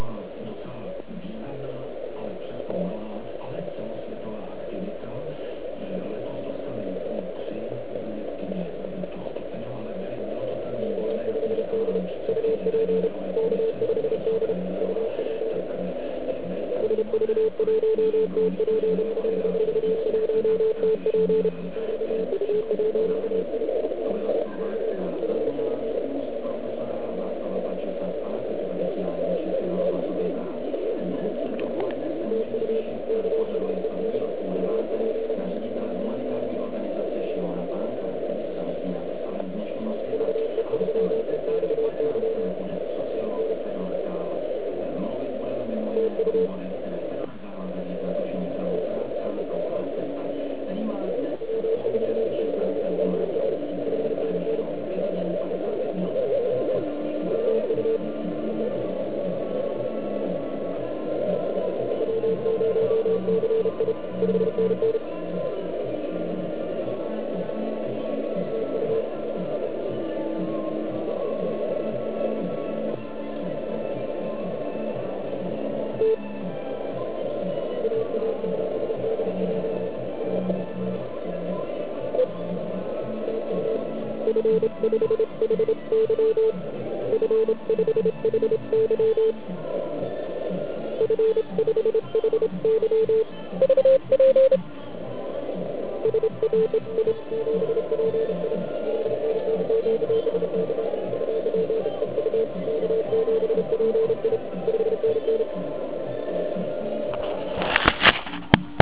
Stupeň postižení EU ops dosahuje už asi maxima, horší to snad ani být nemůže.
Slyšitelnost na 80m je vynikající. Poslechněte si nahrávku, jak jsem to slyšel já na pádlovku.